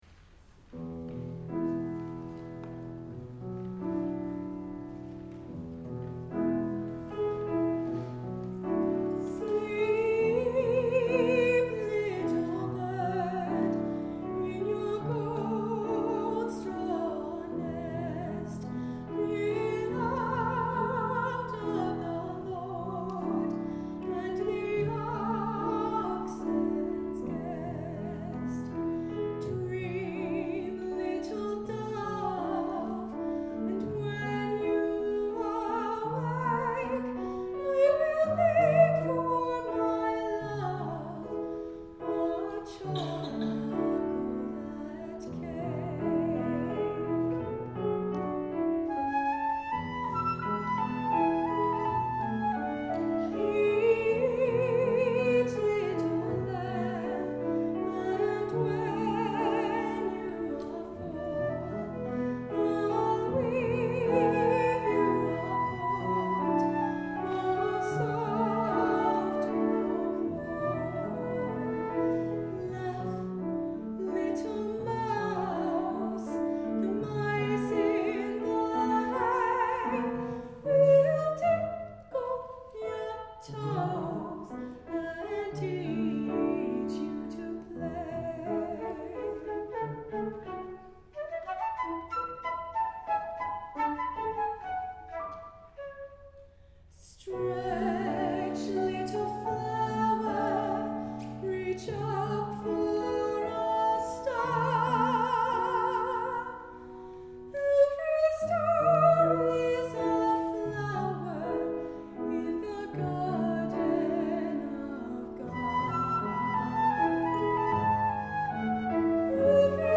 for Soprano, Optional Flute, and Piano (1982)
soprano, flute, and piano version